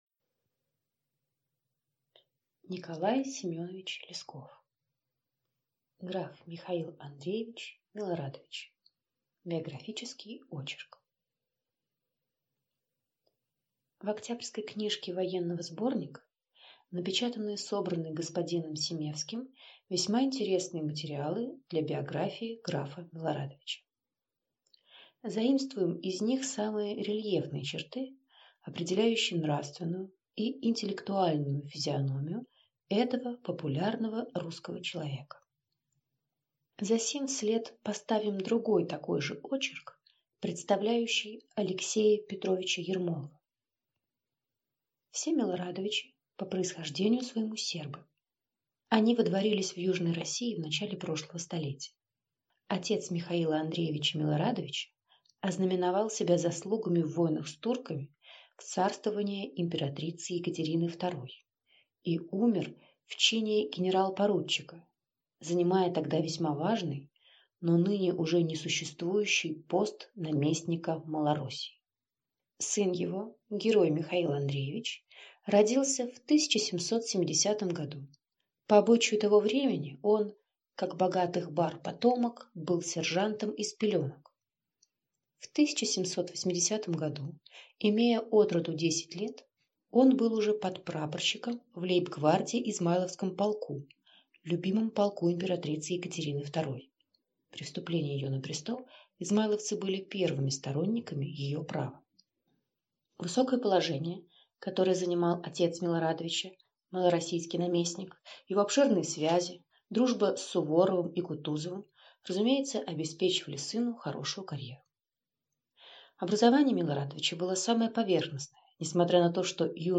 Аудиокнига Граф Михаил Андреевич Милорадович | Библиотека аудиокниг